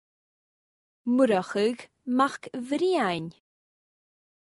Murtagh : Murchadh /MOORAchugh/ ch as “loch”/ This is a hard one.
Note only the first part is Murchadh, the second part is a last name, MacBhriain.